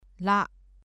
[lâ ]※文字の名前はありません。